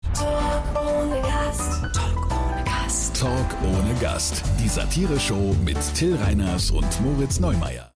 markant, dunkel, sonor, souverän
Mittel minus (25-45)
Station Voice, Vocals (Gesang)